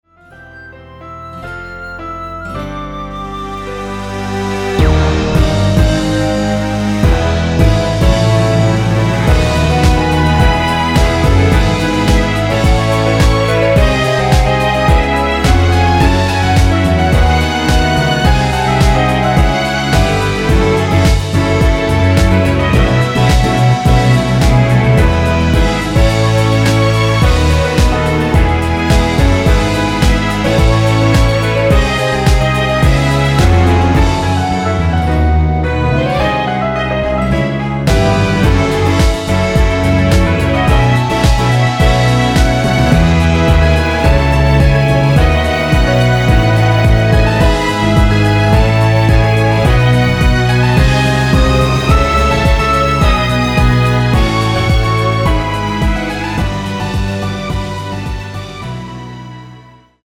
짧은편곡 멜로디 포함된 MR 입니다.
1절후 후렴구로 진행 되게 편곡 하였습니다.(가사및 미리듣기 참조)
Db
앞부분30초, 뒷부분30초씩 편집해서 올려 드리고 있습니다.
중간에 음이 끈어지고 다시 나오는 이유는